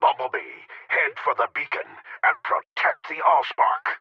File File history File usage A5_ch4_goto2.wav  (WAV audio file, length 4.0 s, 353 kbps overall) Unused Reminder Dialogue This file is an audio rip from a(n) Windows game.